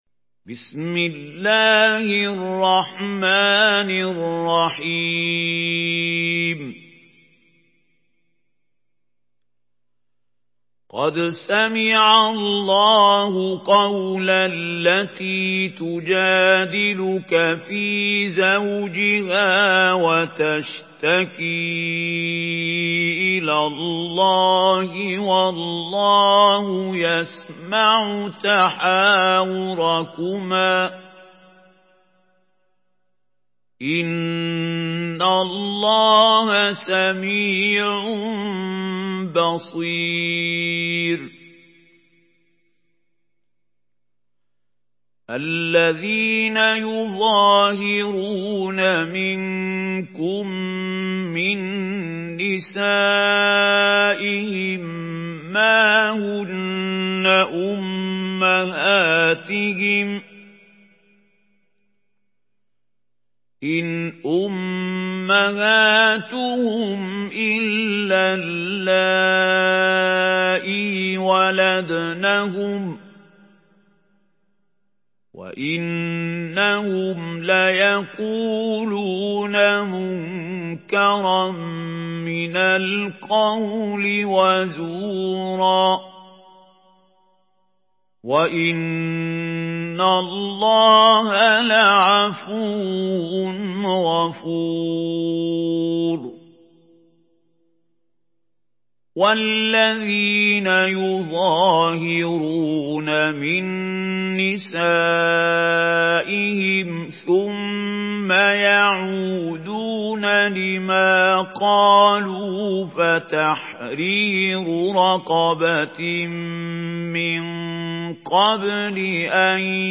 Surah Mücadele MP3 in the Voice of Mahmoud Khalil Al-Hussary in Hafs Narration
Murattal